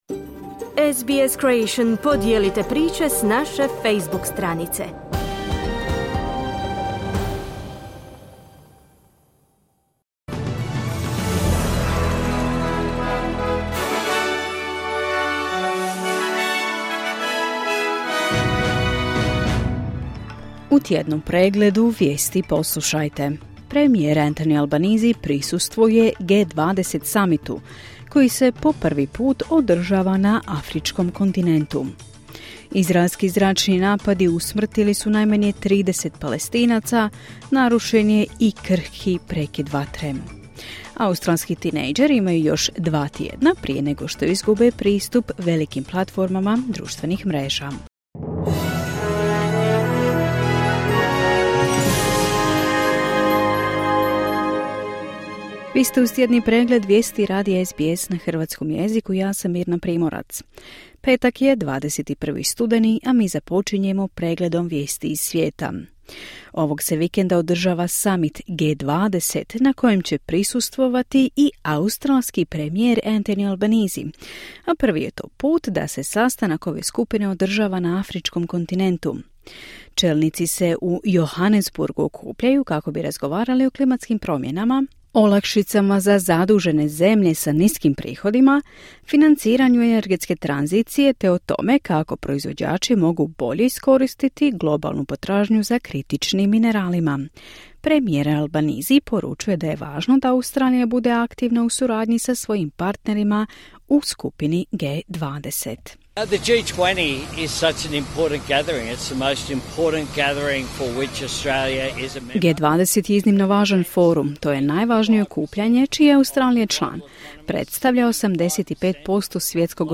Tjedni pregled vijesti, 21.11.2025.
Vijesti radija SBS na hrvatskom jeziku.